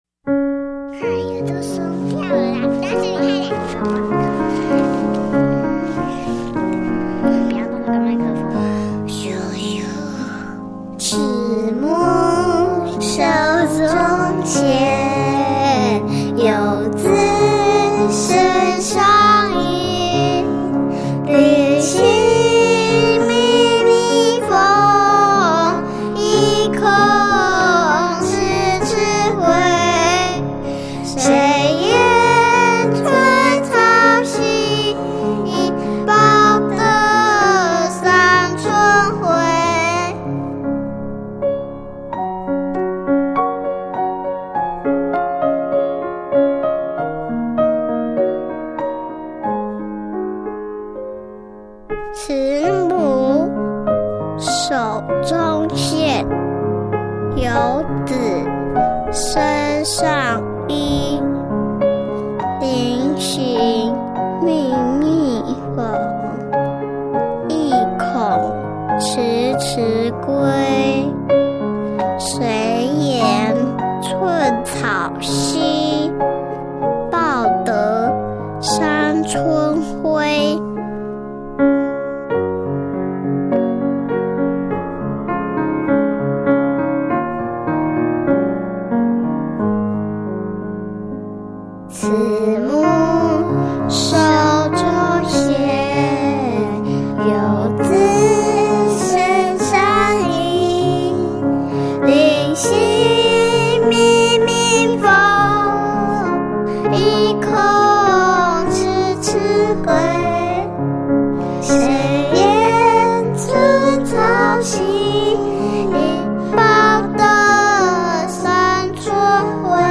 浪漫新世纪音乐
钢琴/长笛